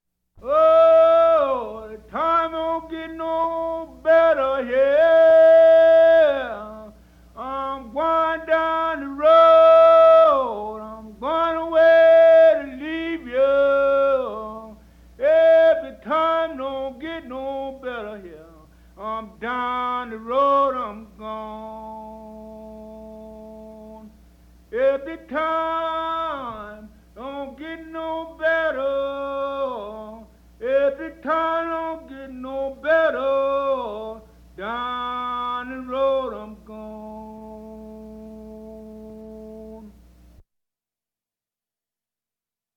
Complaint Call
complaintcall.mp3